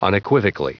Prononciation audio / Fichier audio de UNEQUIVOCALLY en anglais
Prononciation du mot : unequivocally